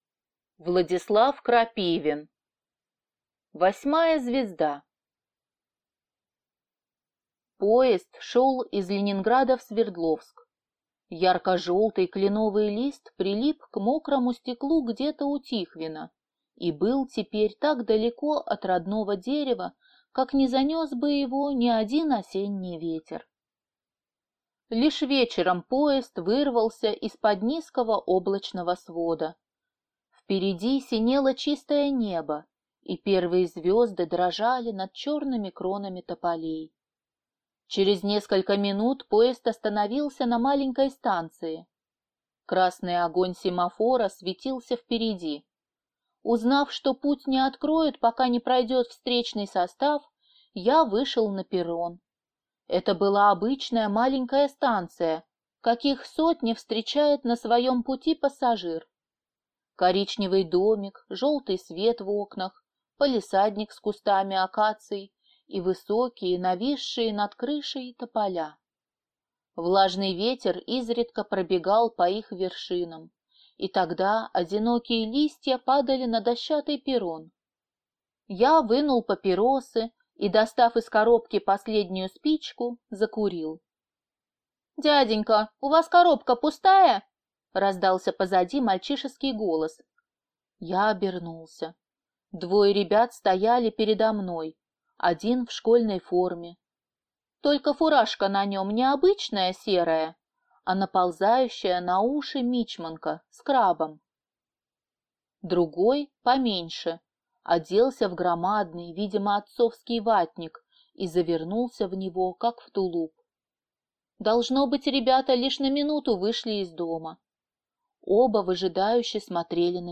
Аудиокнига Восьмая звезда | Библиотека аудиокниг